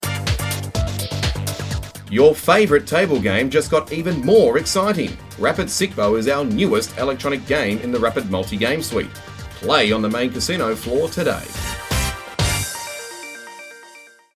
Male
Corporate
Words that describe my voice are Deep, Strong, Projecting.